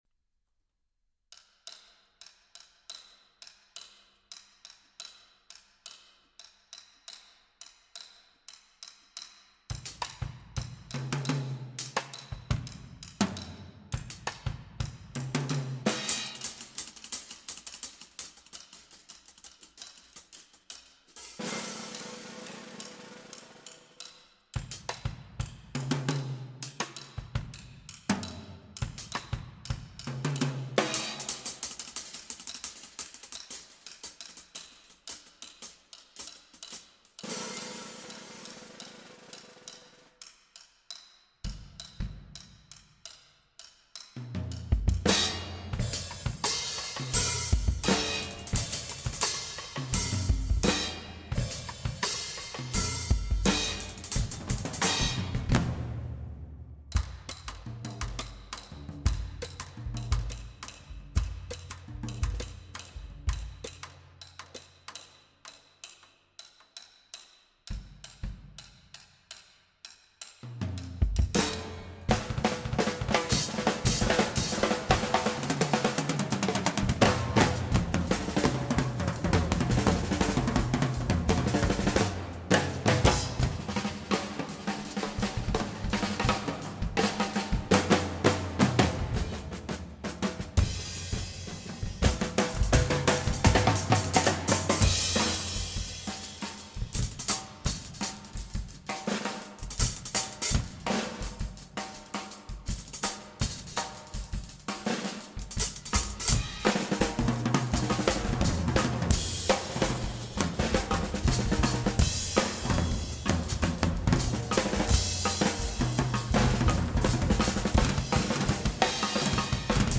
Genre: Percussion Ensemble
4 Drum Set Players: